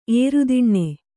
♪ ērudiṇṇe